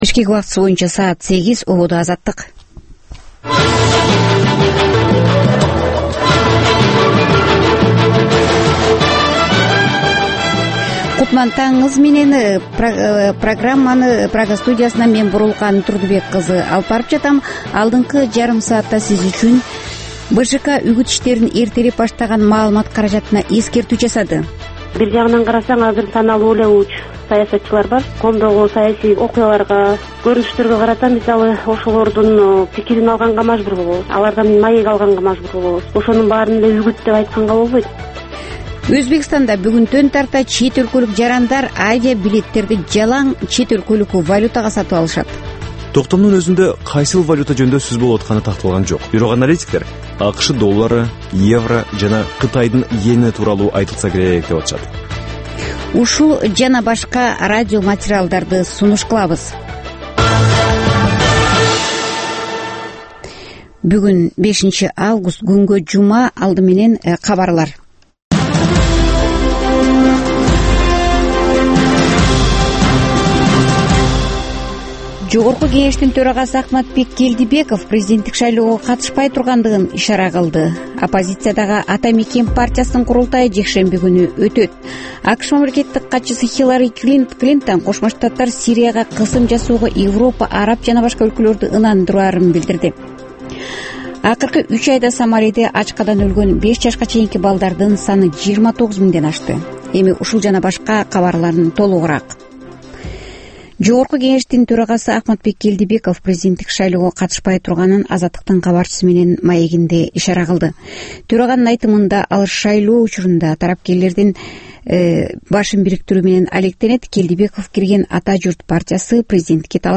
Таңкы 8деги кабарлар